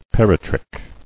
[per·i·trich]